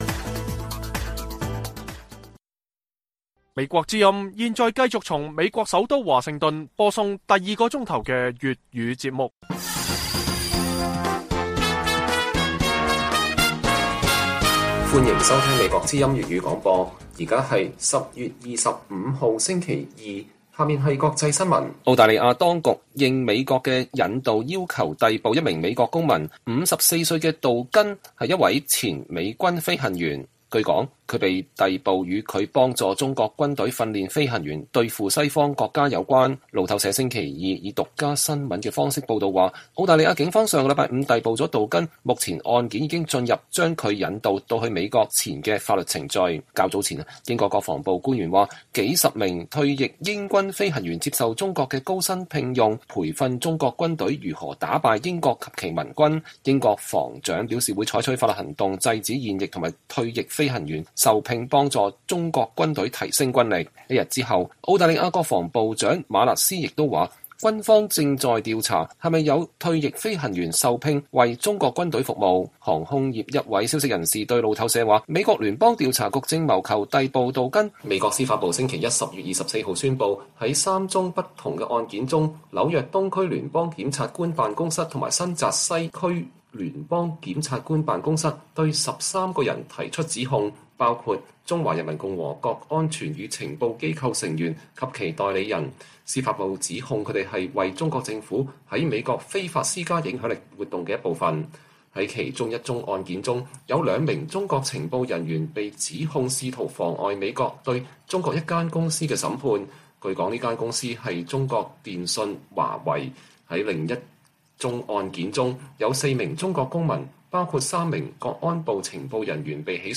粵語新聞 晚上10-11點: 澳大利亞逮捕一名據信為中國軍隊效力的前美軍飛行員